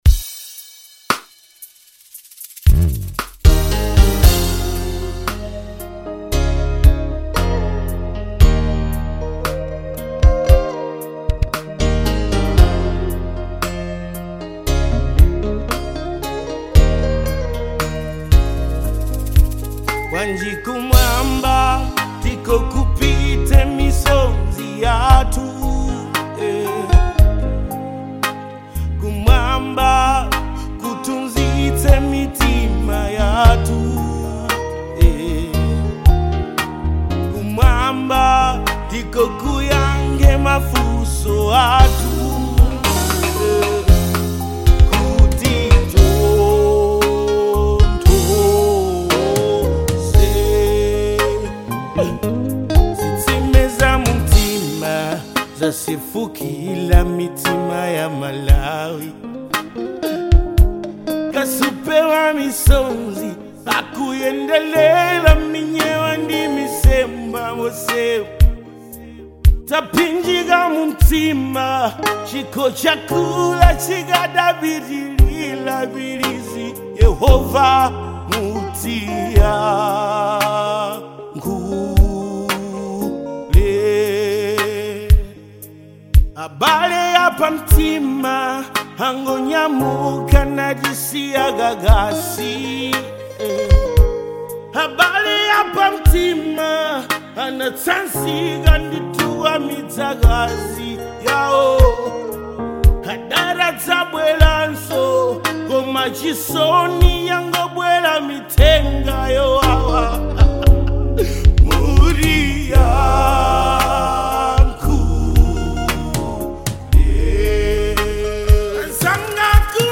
Genre Afrobeats